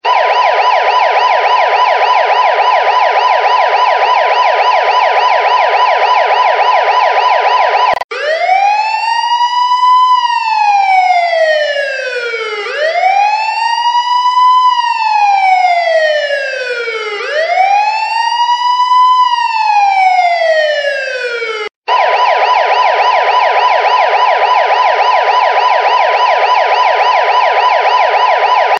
Klingelton Real Police Siren
Kategorien Soundeffekte
real-police-siren-sound.mp3